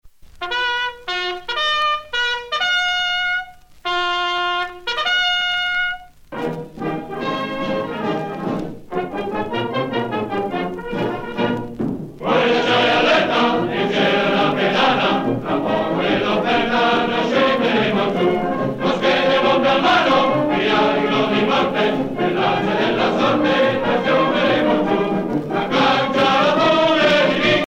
Fonction d'après l'analyste gestuel : à marcher
Usage d'après l'analyste circonstance : militaire
Catégorie Pièce musicale éditée